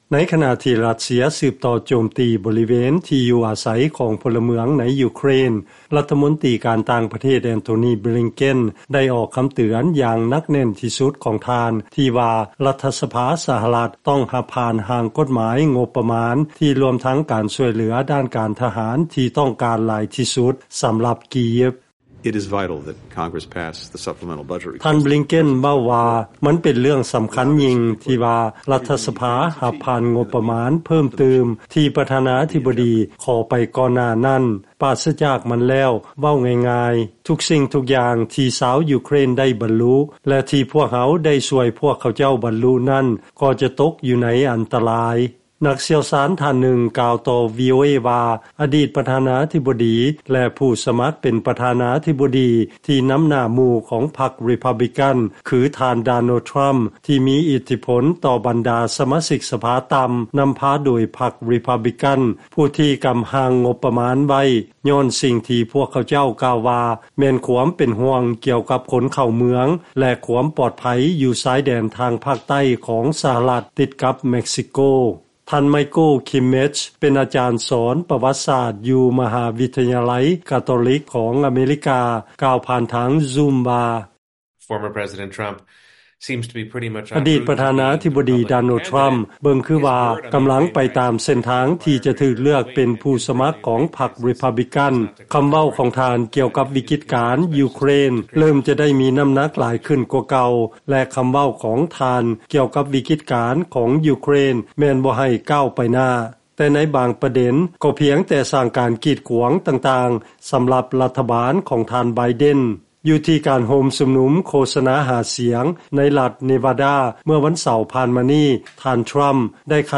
((Antony Blinken, Secretary of State))
At a campaign rally in Nevada on Saturday, Trump opposed Congress passing the budget.
((Donald Trump, Republican Presidential Hopeful))
((Rep. Andy Harris, Republican))
((Rep. Marcy Kaptur, Democrat))